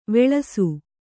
♪ veḷasu